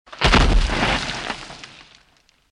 bodyfalldirt02.mp3